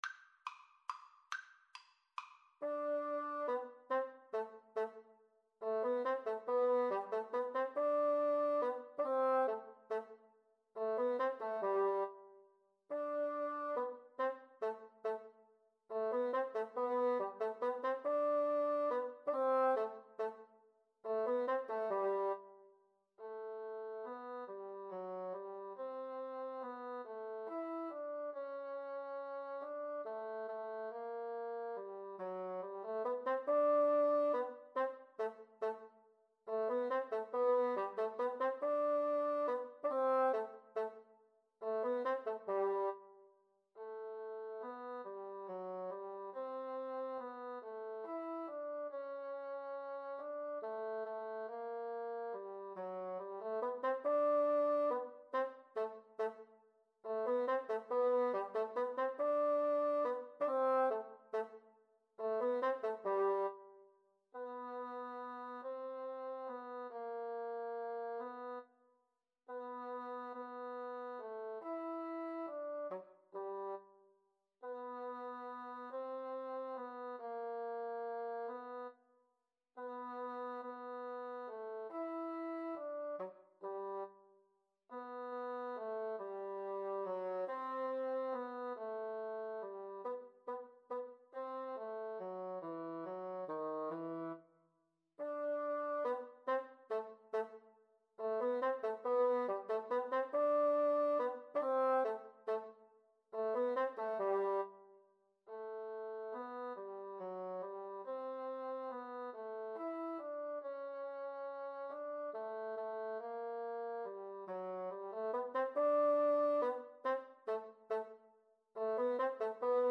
G major (Sounding Pitch) (View more G major Music for Bassoon Duet )
Allegretto - Menuetto =140
Bassoon Duet  (View more Easy Bassoon Duet Music)
Classical (View more Classical Bassoon Duet Music)